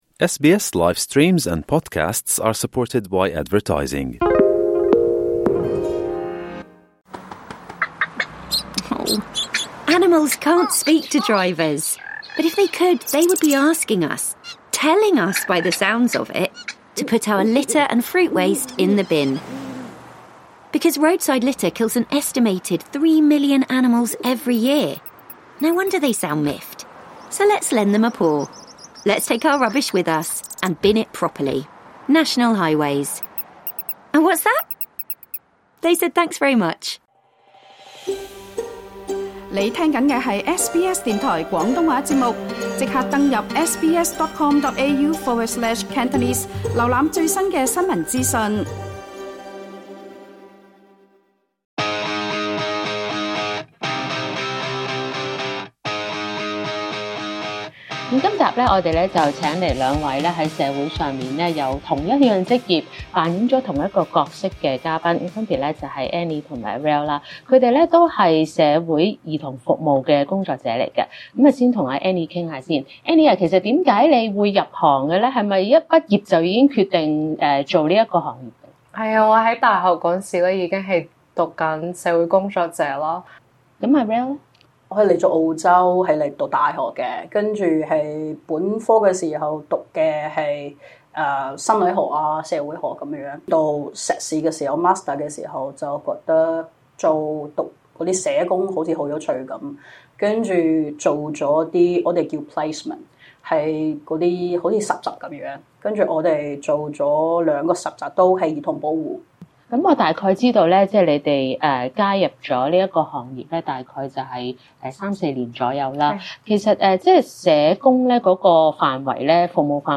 今集【職‧ 夢‧人】，訪問兩位專注兒童服務的社工，負責配對小朋友或青少年到寄養家庭，過程中要照顧兒童的心理及日常需要，又要支援寄養家庭與受助兒童的語言和文化等差異，亦要協調與原生家庭的溝通。